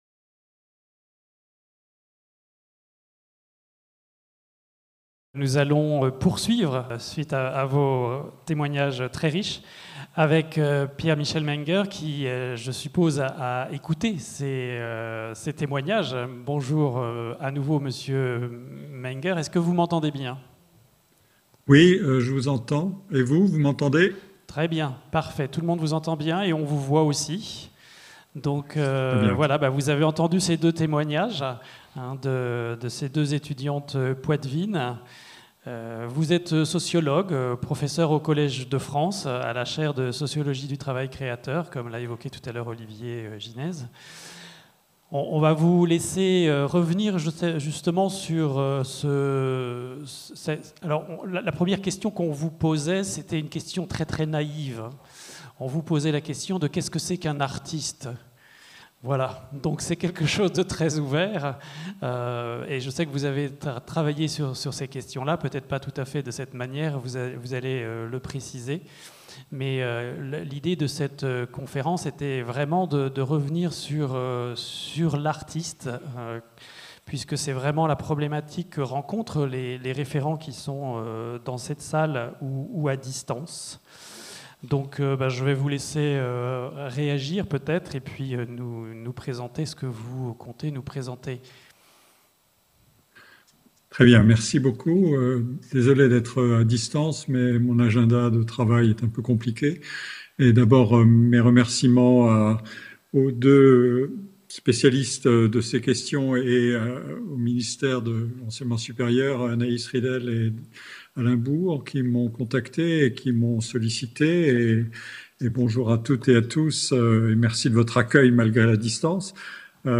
Conférence de Piere-Michel Menger | Canal U